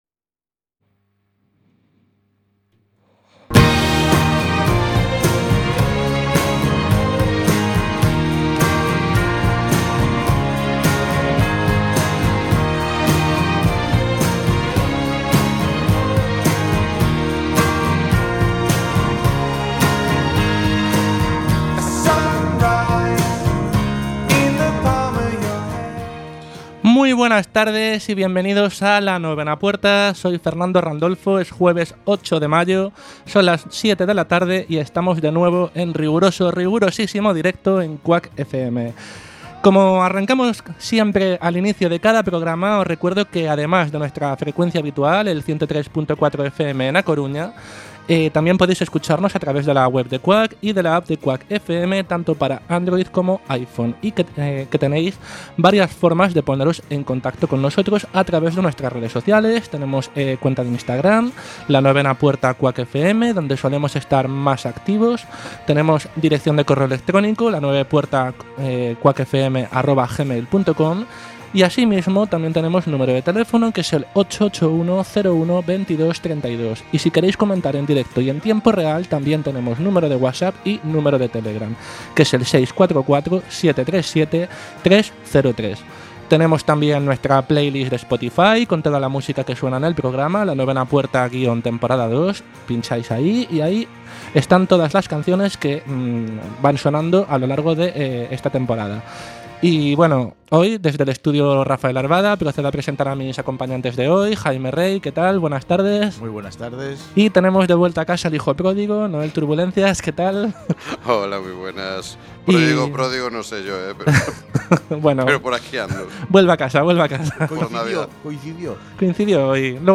Programa de opinión y actualidad en el que se tratan temas diversos para debatir entre los colaboradores, con algún invitado relacionado con alguno de los temas a tratar en el programa y que además cuenta con una agenda de planes de ocio en la ciudad y alguna recomendación musical y de cine/series/libros.